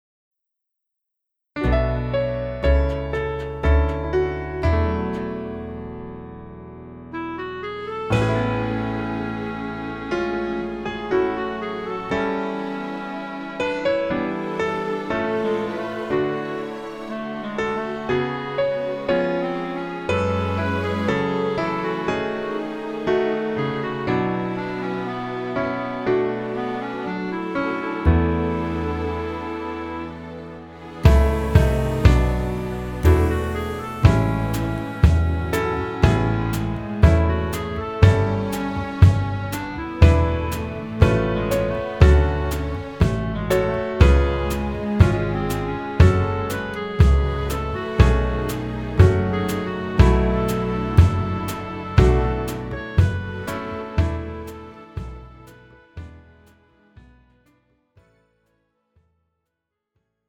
음정 -1키 3:54
장르 가요 구분 Pro MR